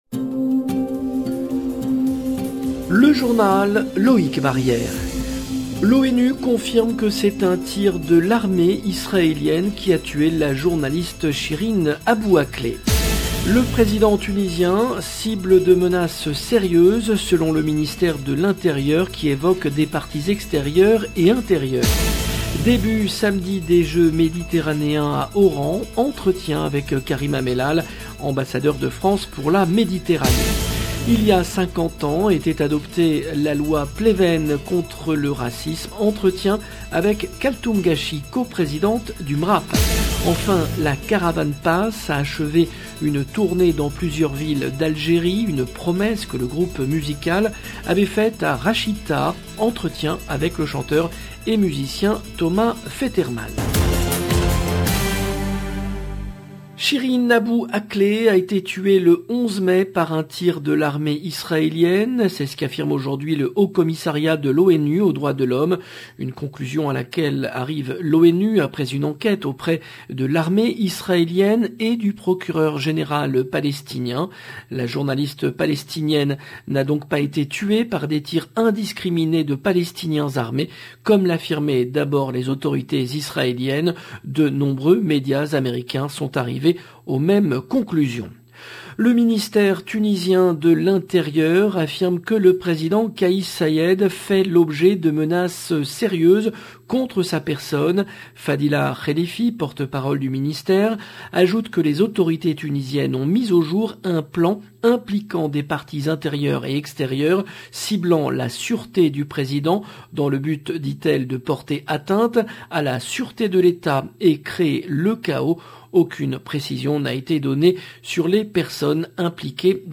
Journal présenté par
Entretien avec Karim Amellal, ambassadeur de France pour la Méditerranée Il y a 50 ans était adoptée la loi Pleven contre le racisme.